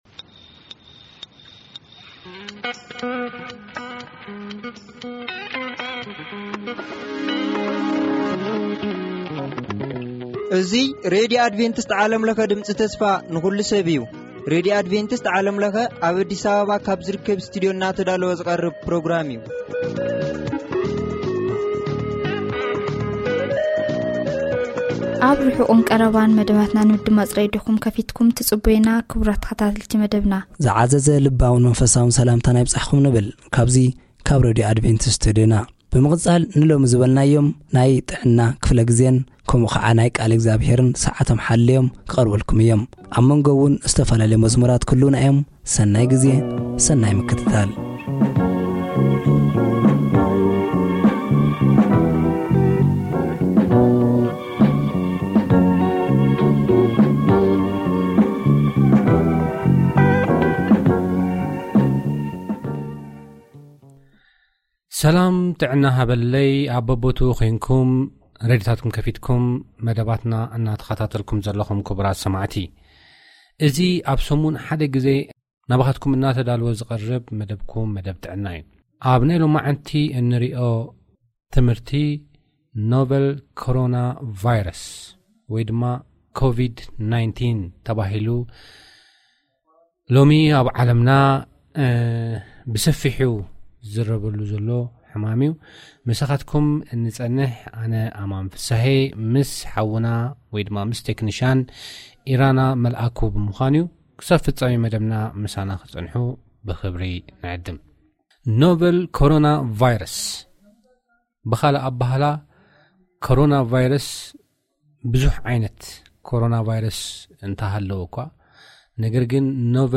Daily Tigrinya (ትግርኛ) radio programs for Eritrea & Ethiopia, brought to you by Adventist World Radio